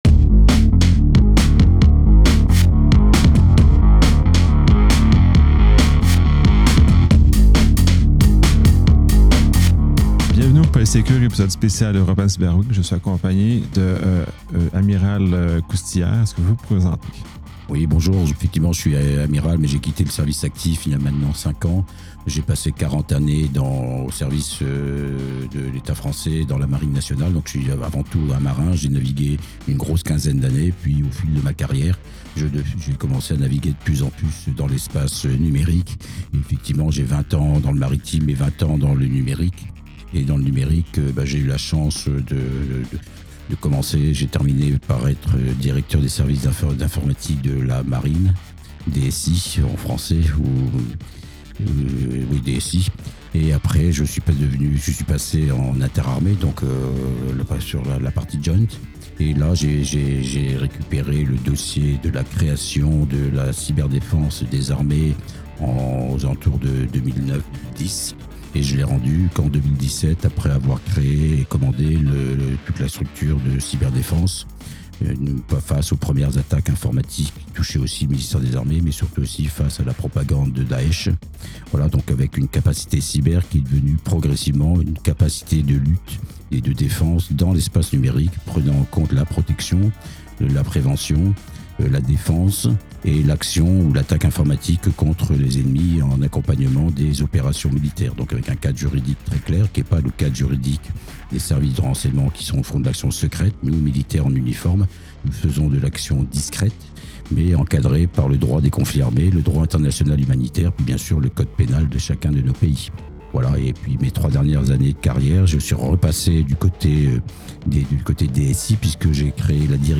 Locaux réels par European Cyber Week